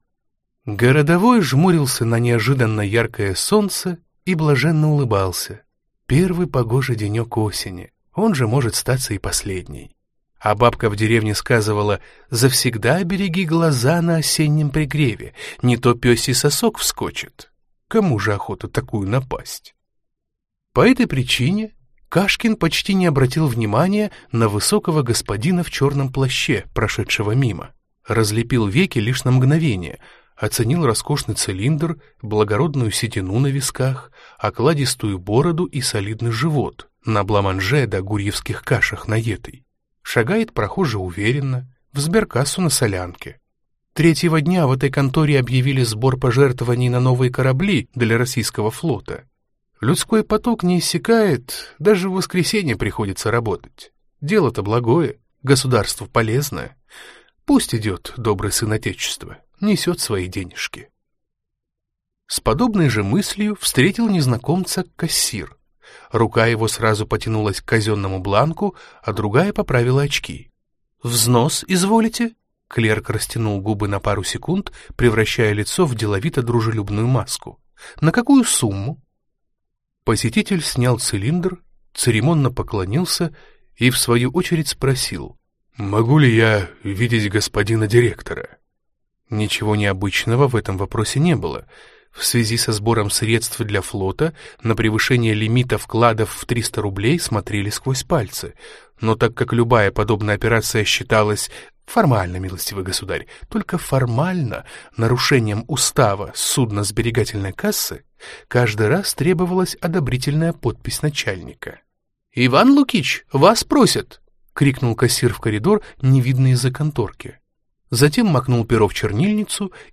Аудиокнига Гремучий студень | Библиотека аудиокниг
Прослушать и бесплатно скачать фрагмент аудиокниги